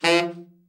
TENOR SN  10.wav